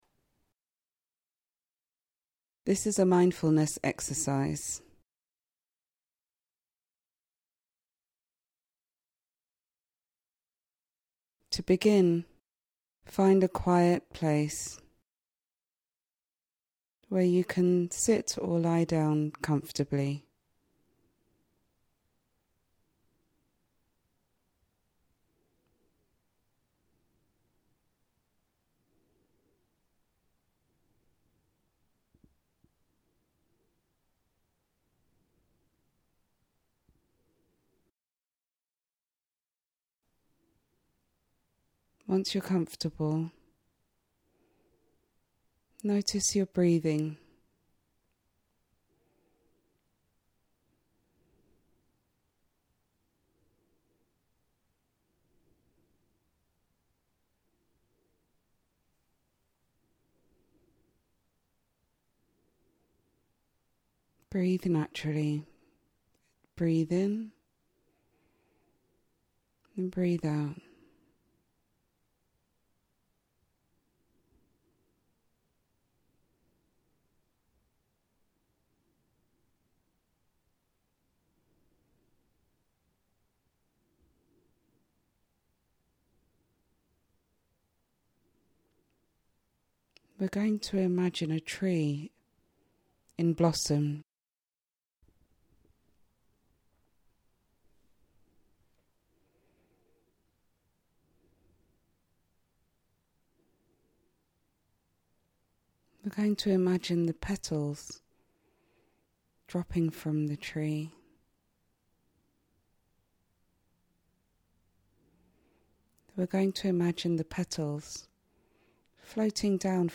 I have personally recorded a relaxation technique that are free for you to download here. [Petal relaxation exercise] Relaxation can help reduce physical reactions.
Petal-Exercise.mp3